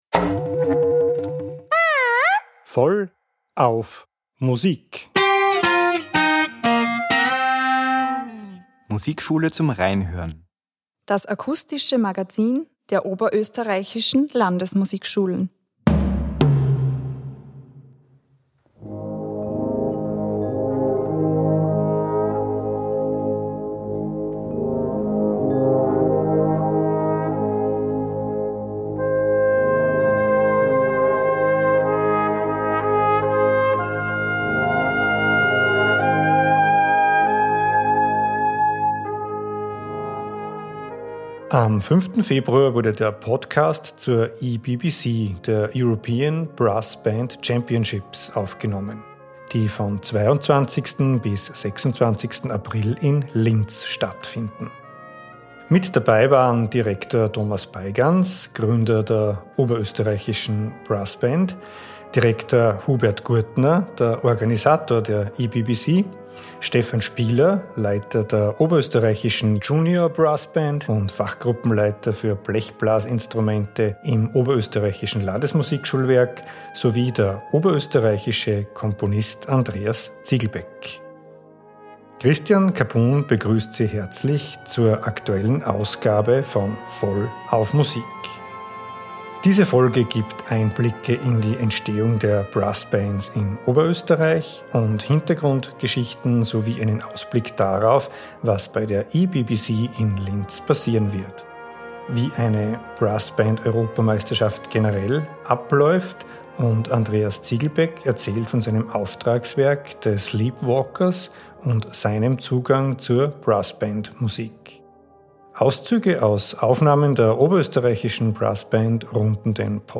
Auszüge aus Aufnahmen der Brass Band Oberösterreich runden den Podcast ab.